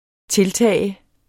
Udtale [ -ˌtæˀ ]